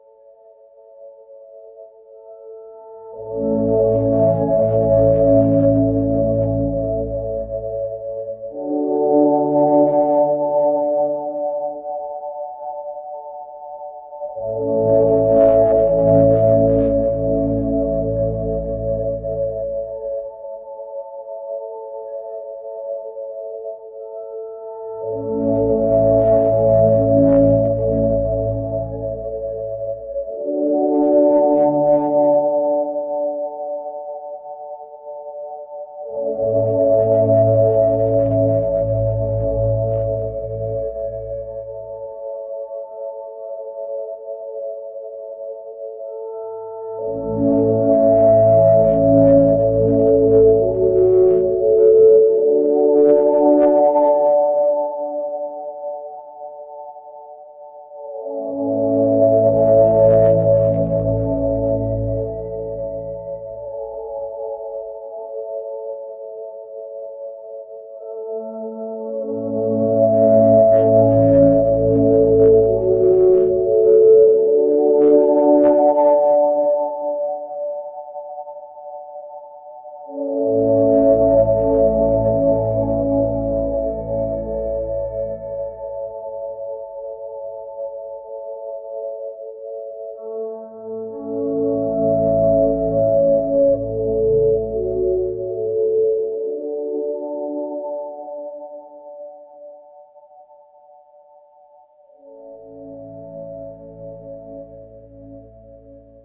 Nature & Forest Ambience